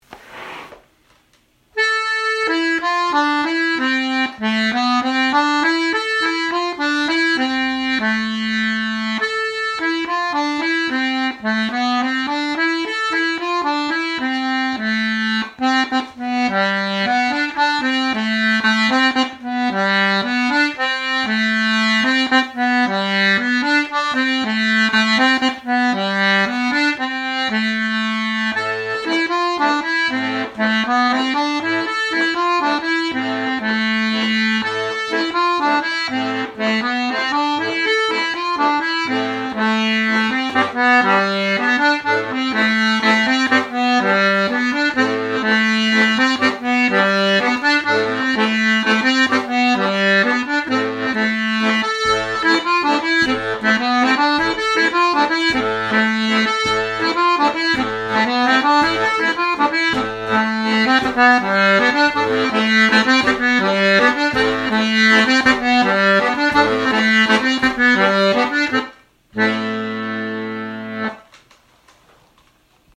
Durspelsversion!